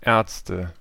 Ääntäminen
Ääntäminen Tuntematon aksentti: IPA: /ˈɛːɐ̯t͡stə/ IPA: /ˈɛːɐ̯ʦtə/ Haettu sana löytyi näillä lähdekielillä: saksa Käännöksiä ei löytynyt valitulle kohdekielelle. Ärzte on sanan Arzt monikko.